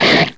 strike06.wav